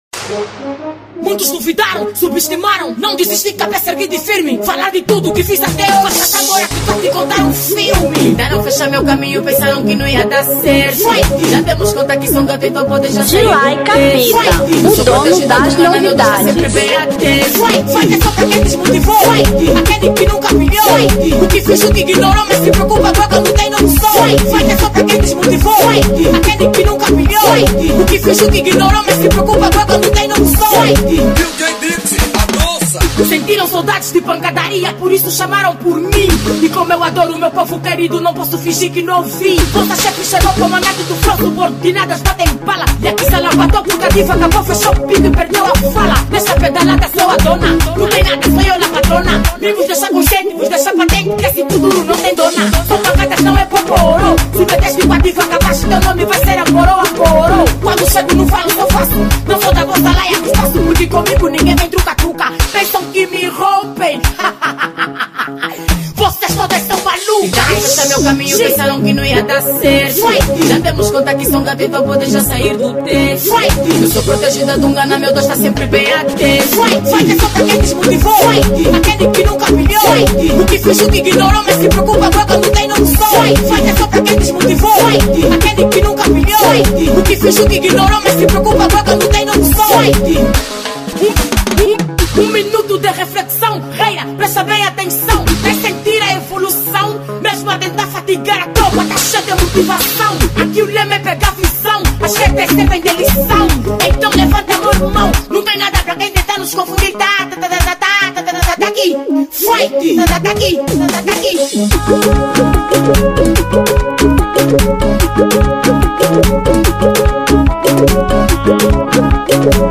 Kuduro 2025